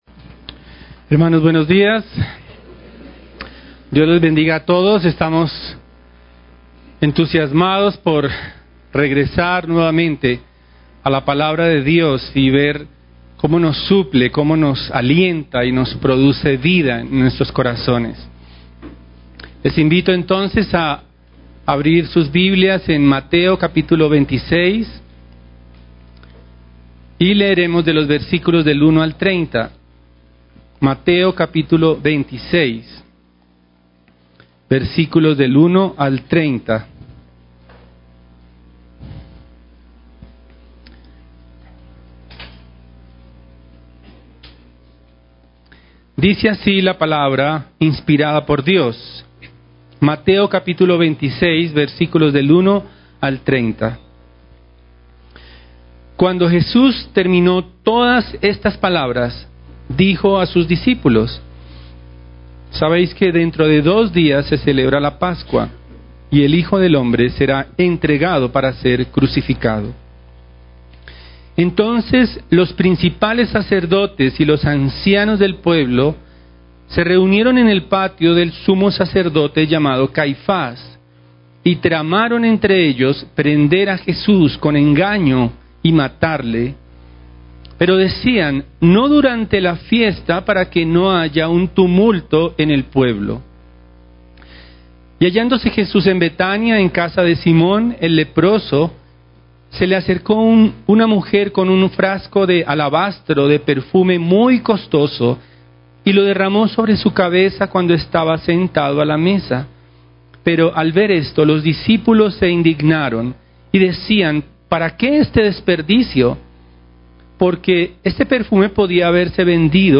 Sermons – Iglesia Bautista Renacer Bogotá